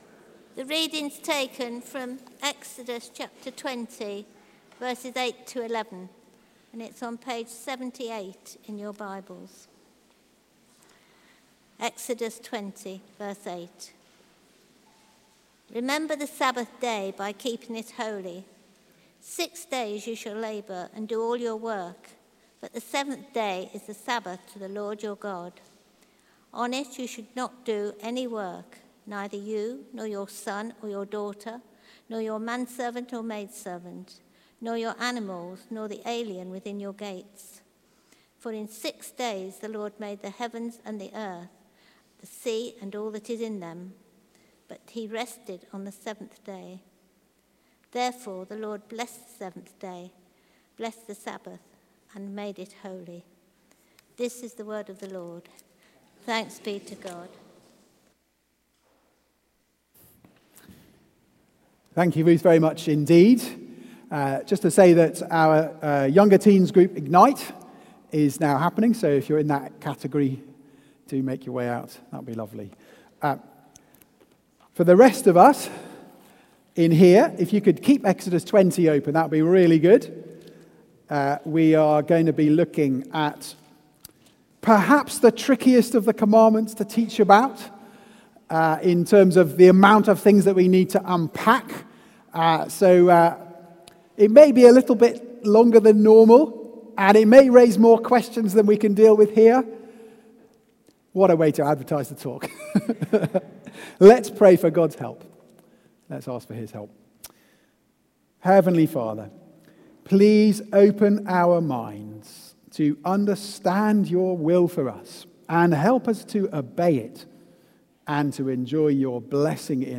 Series: The Ten Commandments Theme: The Rest you've been Missing: Embracing the Sabbath as God's Gif Sermon